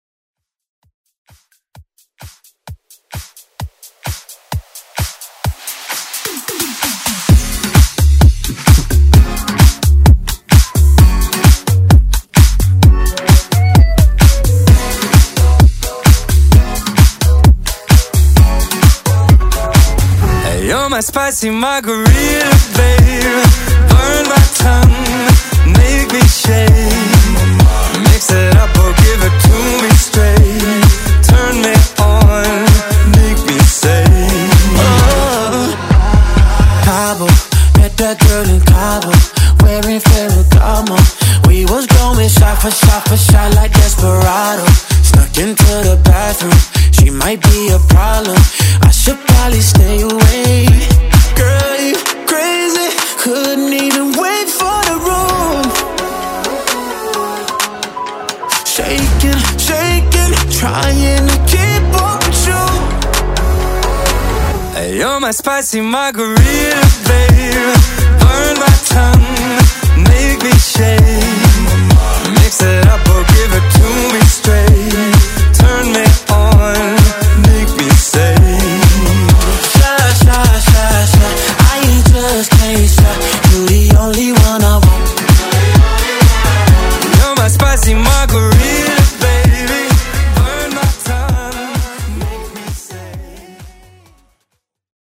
Genre: EDM
Clean BPM: 128 Time